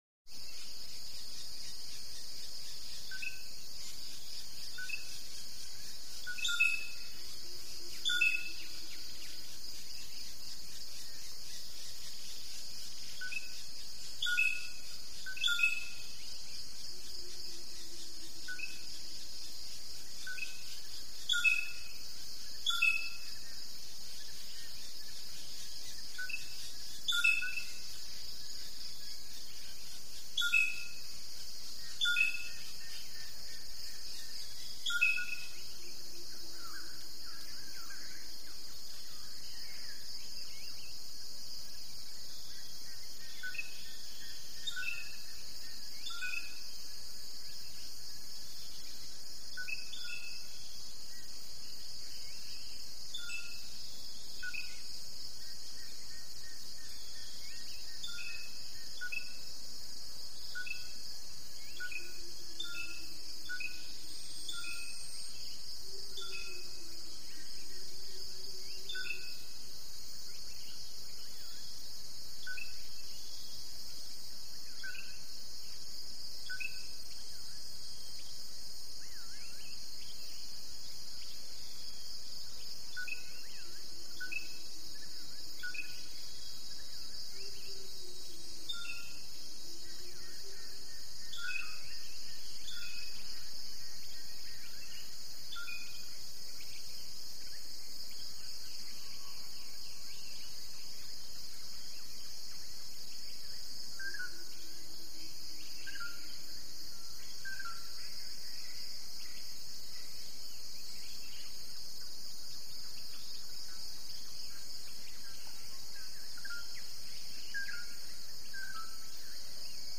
Jungle Atmosphere | Sneak On The Lot
Koh Samui, Thailand - Dawn Jungle Atmosphere - Birds, Insects, Distant Monkey Nature Ambience, Asian